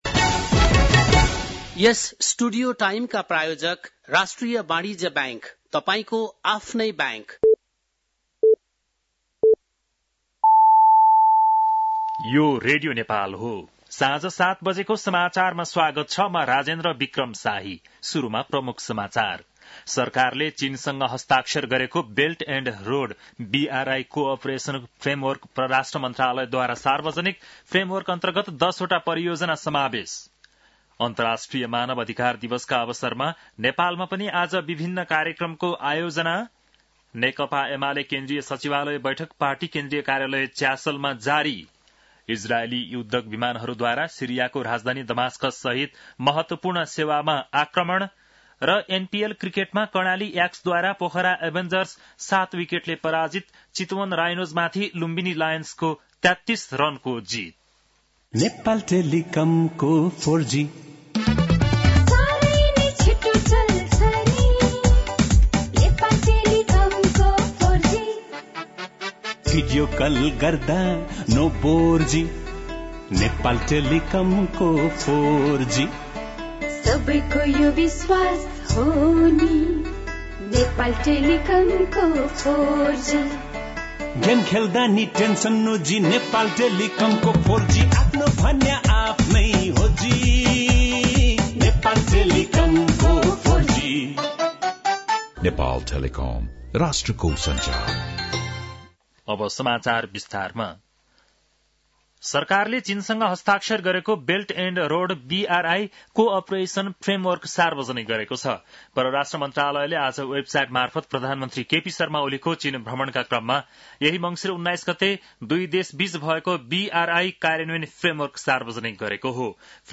बेलुकी ७ बजेको नेपाली समाचार : २६ मंसिर , २०८१
7-pm-nepali-news-8-25.mp3